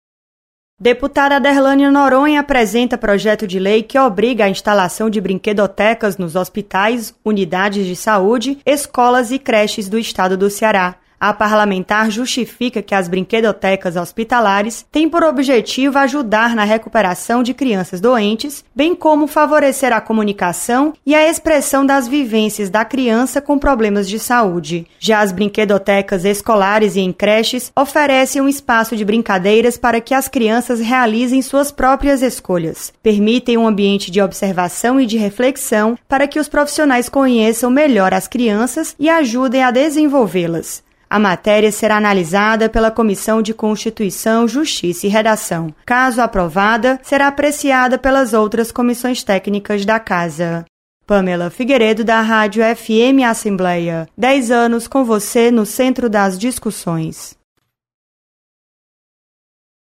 Projeto prevê instalação de brinquedotecas em hospitais e creches no Ceará. Repórter